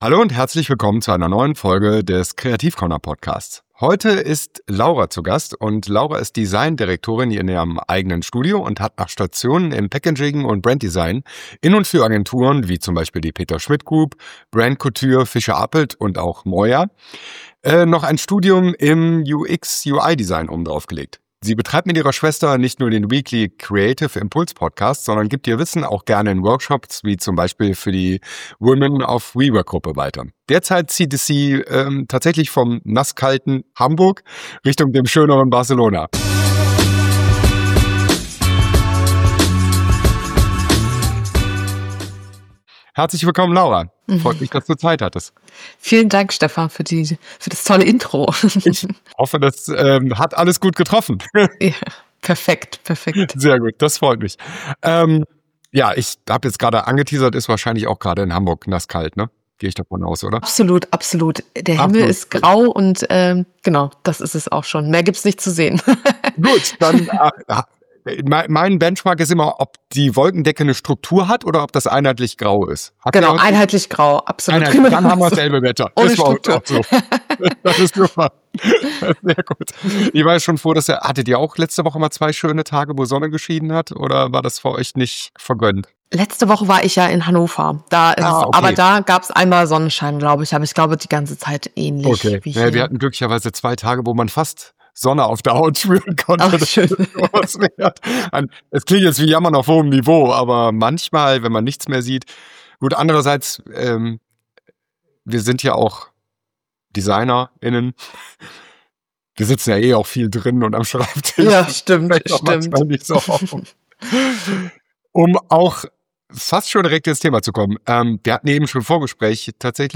Ein Gespräch über Arbeit, Gestaltung und die Frage, wie man als Designer:in langfristig zufrieden bleibt.